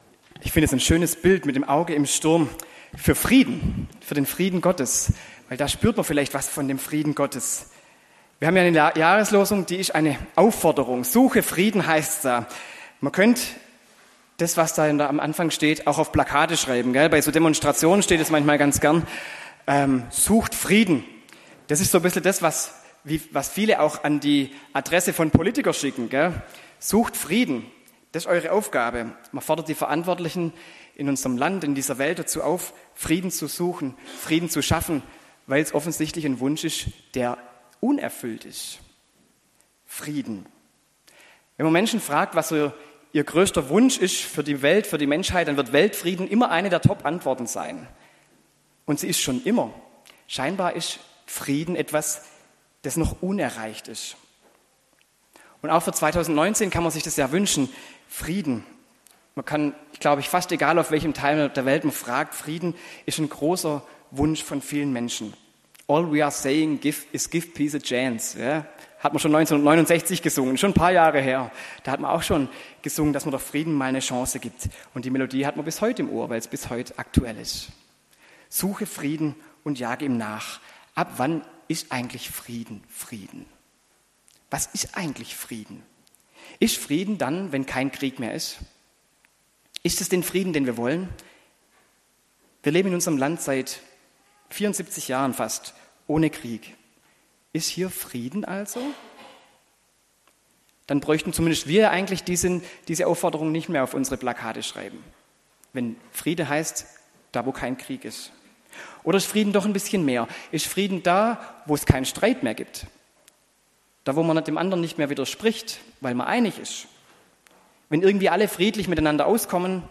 Dialogpredigt zur Jahreslosung: Suche Frieden und jage ihm nach (Psalm 34, 15)
dialogpredigt-zur-jahreslosung-suche-frieden-und-jage-ihm-nach-psalm-34-15